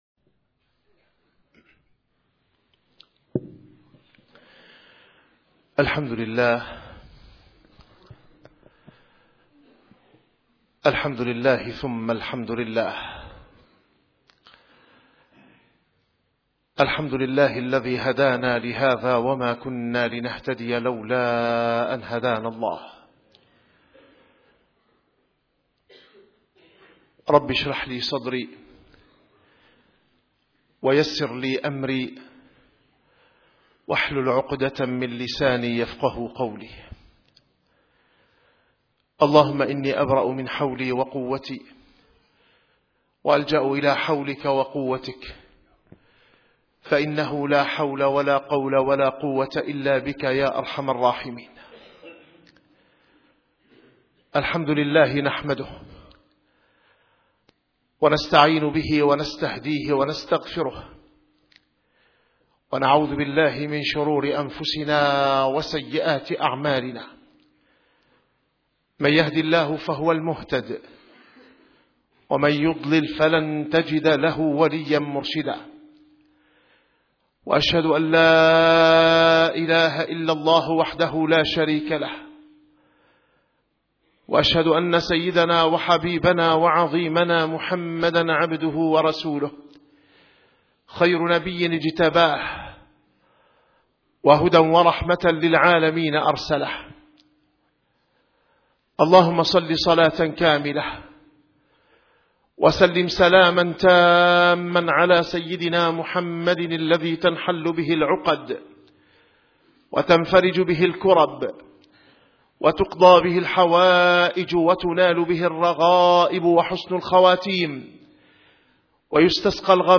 - الخطب - الهجرة النبوية ( دعوة وسلوك )